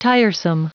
Prononciation du mot : tiresome